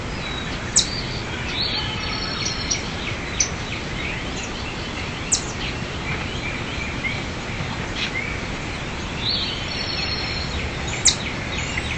دانلود آهنگ موش خرمایی در جنگل از افکت صوتی انسان و موجودات زنده
جلوه های صوتی
دانلود صدای موش خرمایی در جنگل از ساعد نیوز با لینک مستقیم و کیفیت بالا